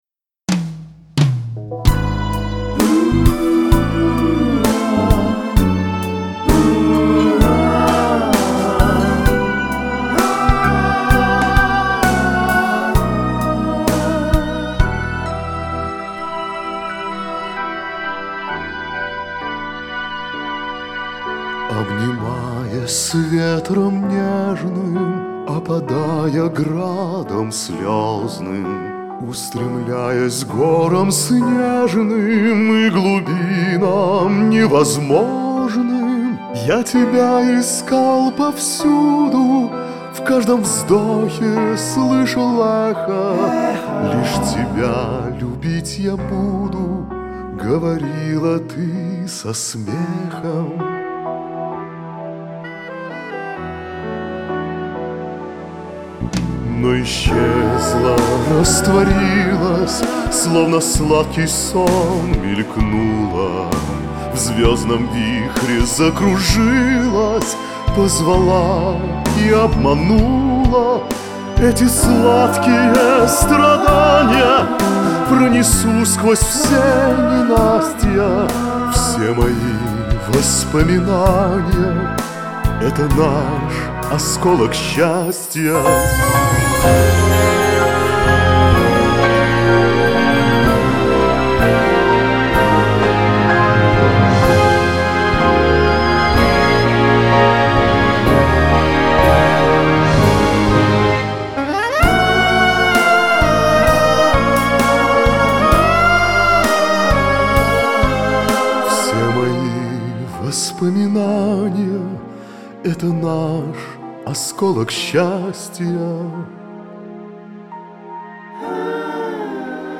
бэк-вокал